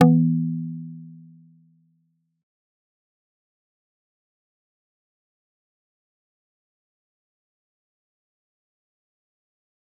G_Kalimba-E3-mf.wav